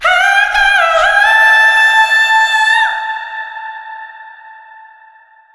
SCREAM12  -L.wav